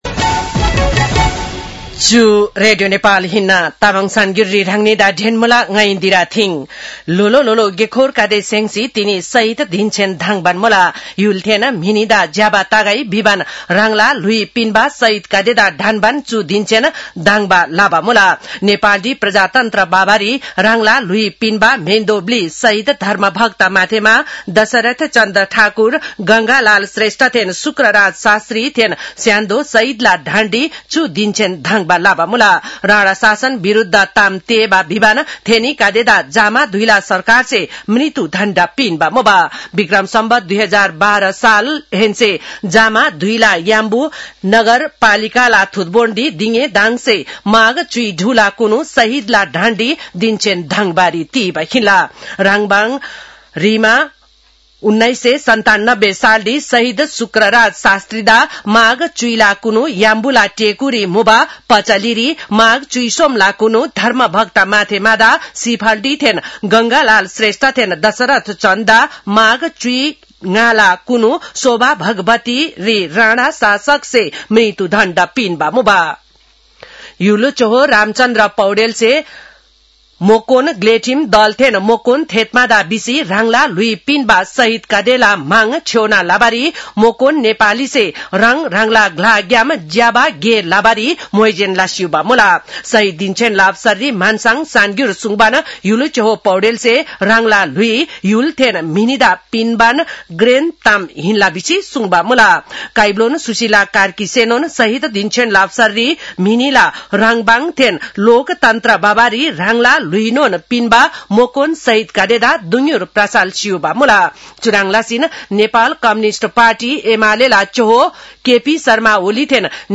तामाङ भाषाको समाचार : १६ माघ , २०८२
Tamang-news-10-16.mp3